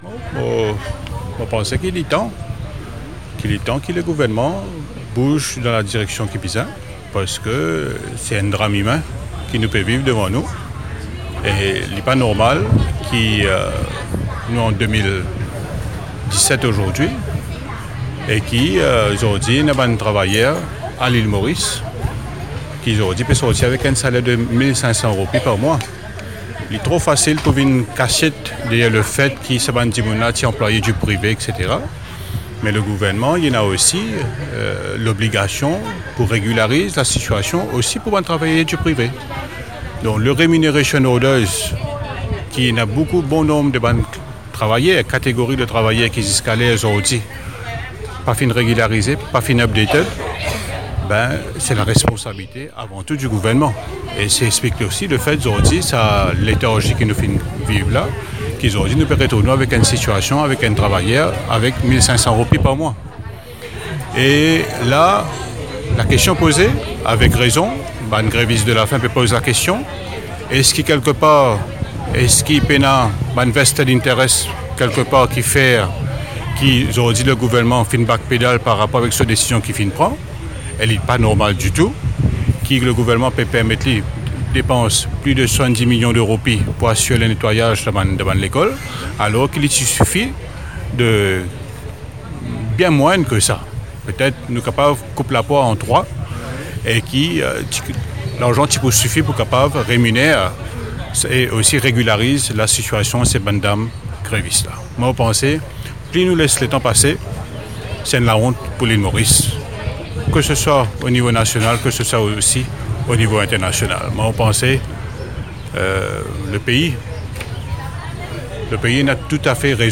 Le député indépendant Kavi Ramano s’est rendu, ce mardi 17 octobre 2017 au jardin de la Compagnie où des femmes cleaners font une grève de la faim. L’élu de Belle-Rose-Quatre-Bornes déclare qu’il est temps que le gouvernement bouge dans la direction qu’il faut.